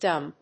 ダブリューディー‐エム